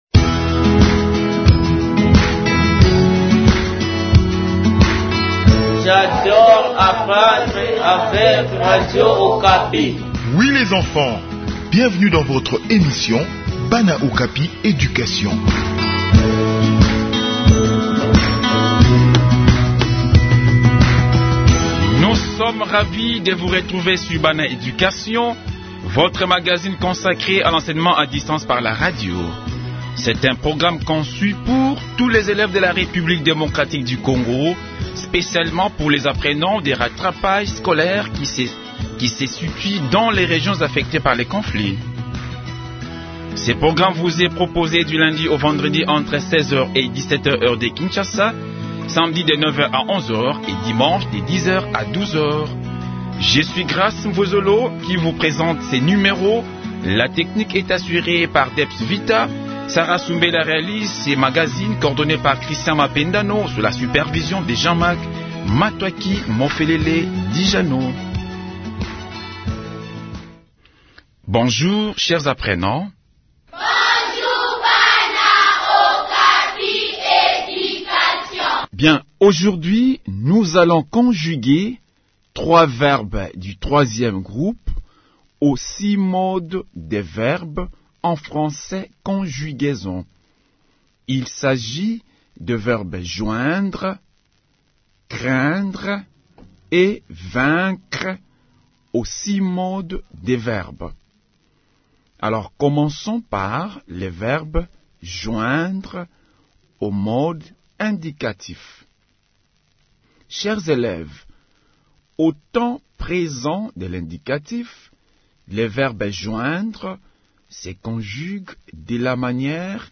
Enseignement à distance : leçon de conjugaison des verbes Joindre, Craindre et Vaincre